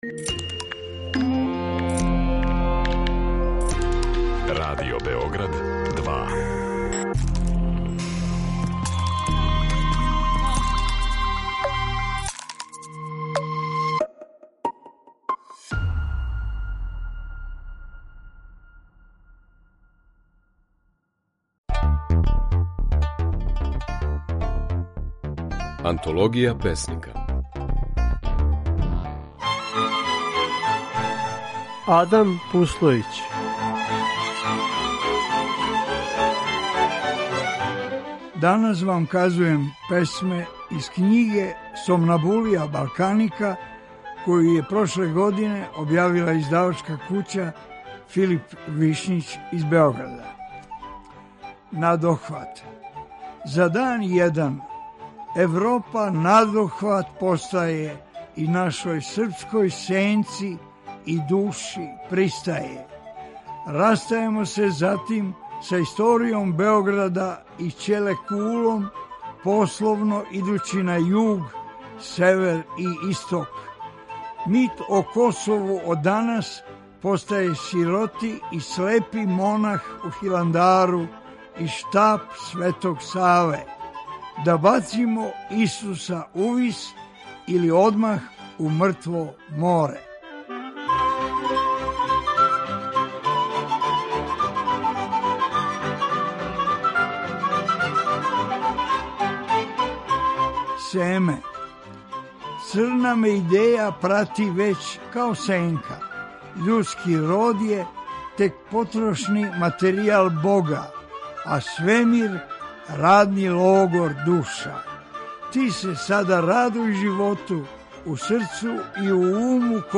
У данашњој емисији можете чути како своје стихове говори песник
Емитујемо снимке на којима своје стихове говоре наши познати песници